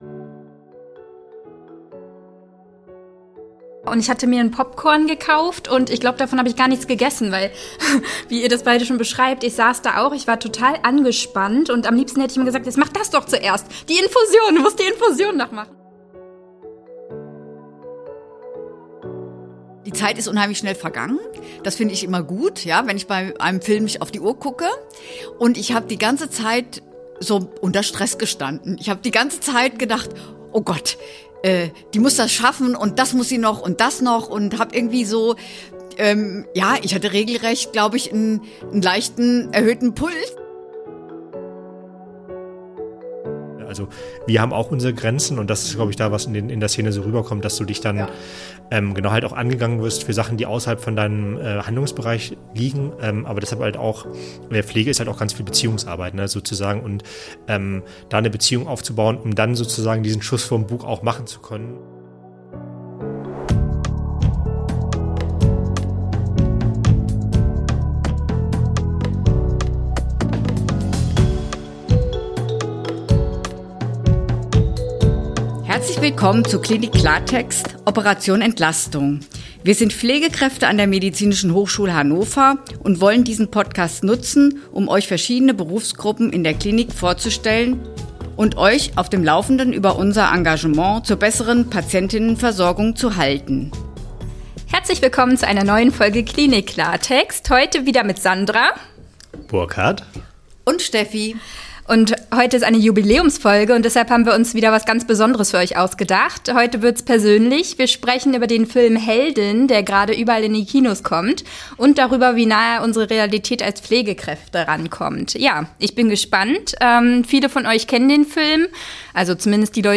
Beschreibung vor 11 Monaten Zur 40. Jubiläumsfolge haben sich unsere drei Podcast-Hosts zusammengesetzt und über den Kinofilm Heldin gesprochen. In Heldin begleiten die Zuschauer*innen die Pflegekraft Floria während ihrer Spätschicht in einem Schweizer Krankenhaus. In dieser Folge erzählen unsere Hosts, wie sie den Film erlebt haben – und geben dabei spannende Einblicke in ihren eigenen Berufsalltag.